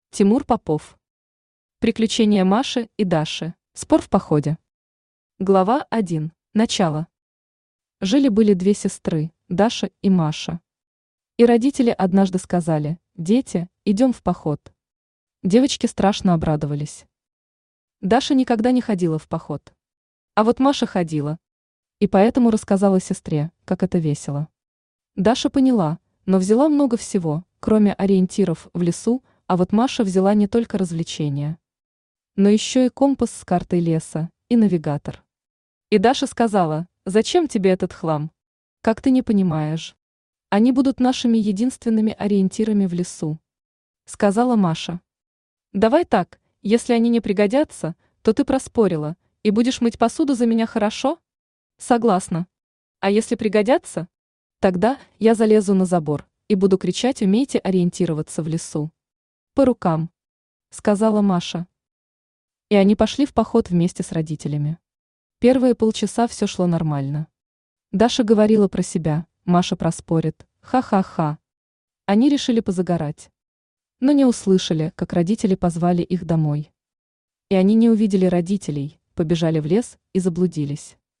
Аудиокнига Приключения Маши и Даши | Библиотека аудиокниг
Aудиокнига Приключения Маши и Даши Автор Тимур Никитович Попов Читает аудиокнигу Авточтец ЛитРес.